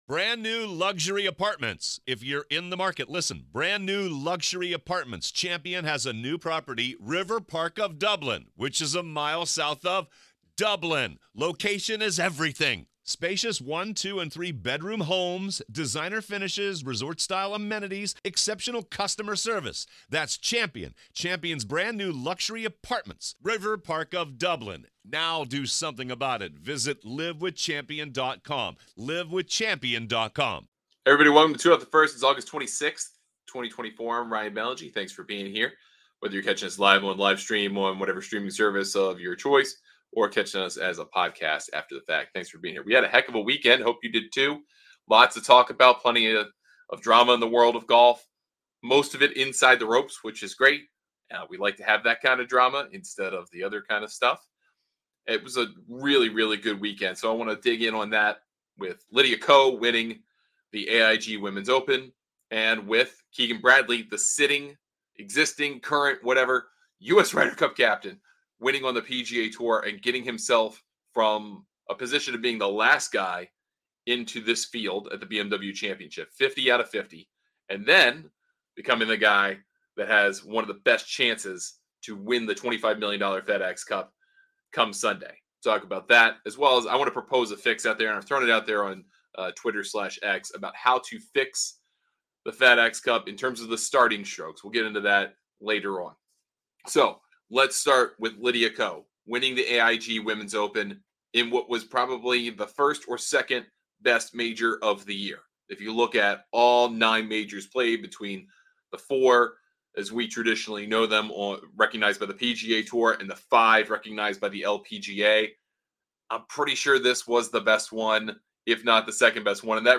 On today's LIVE show